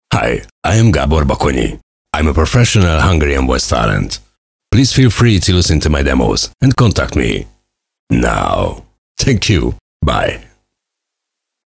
deep, cool, wry, energetic, announcer, sturdy, authoritative, corporate,
Sprechprobe: Sonstiges (Muttersprache):